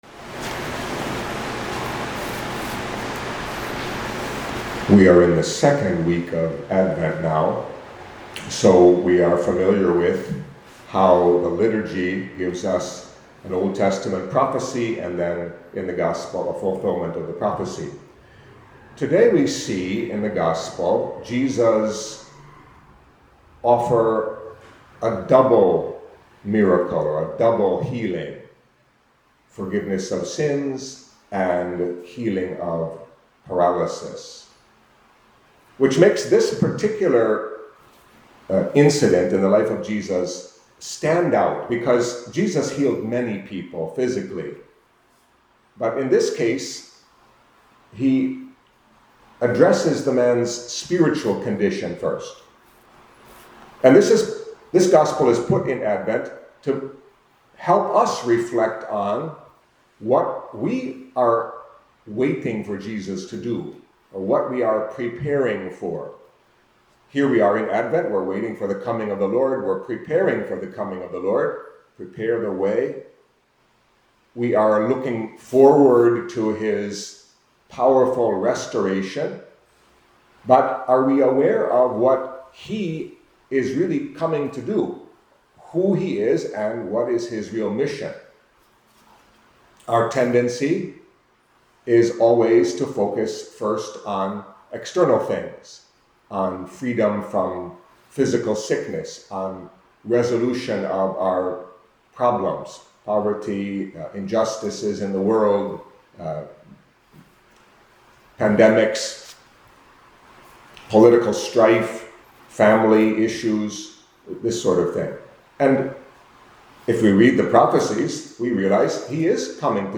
Catholic Mass homily for Monday of the Second Week of Advent